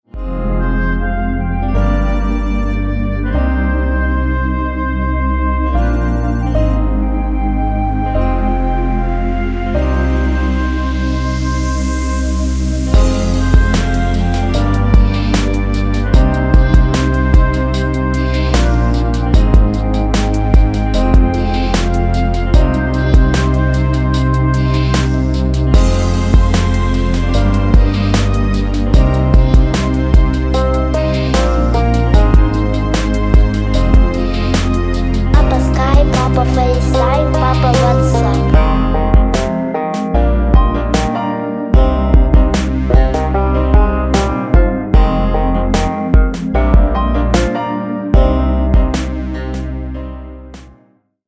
Минус